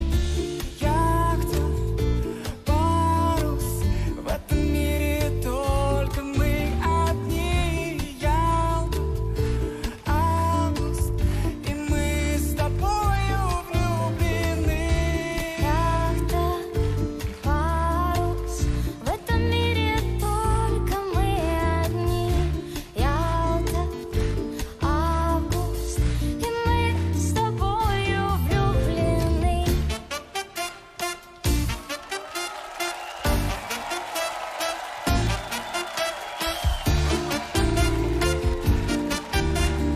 • Качество: 128, Stereo
поп
мужской вокал
женский вокал
спокойные
дуэт
Cover